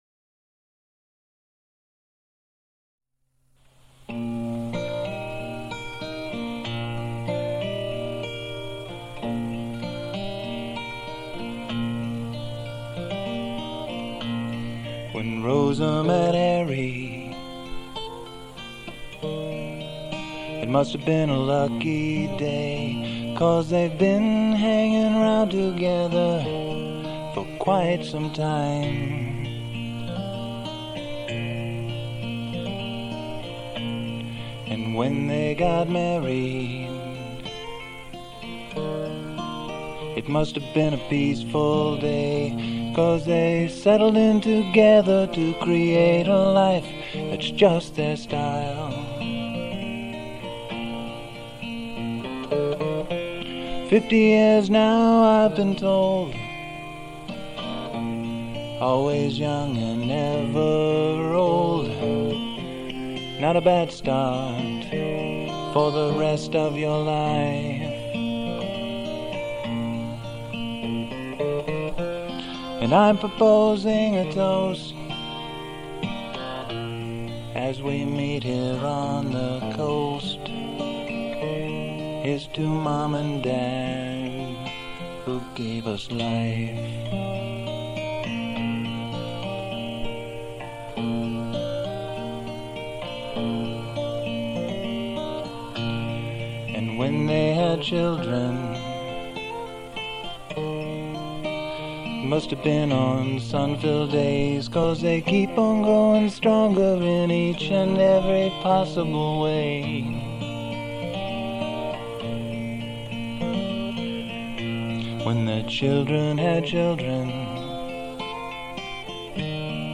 The sound is appropriately home-grown and folksy, which was perfect for the occasion.
Guitar